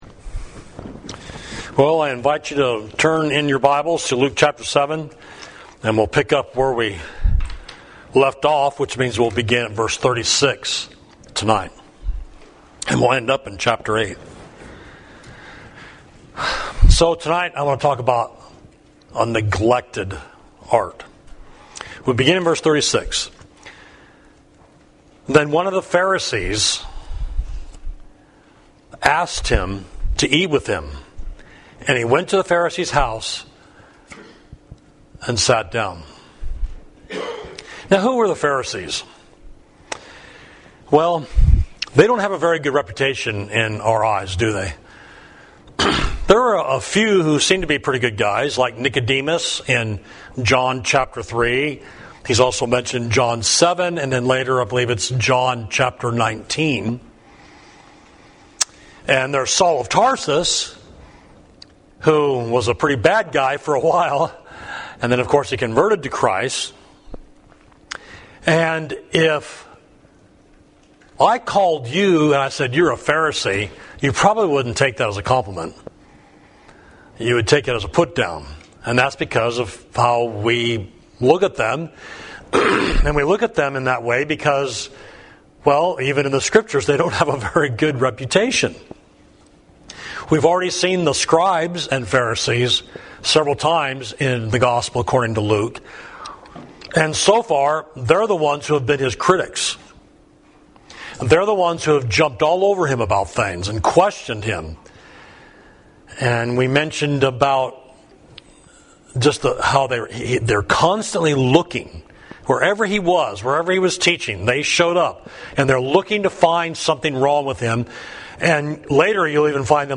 Sermon: A Neglected Art, Luke 7.36–8.3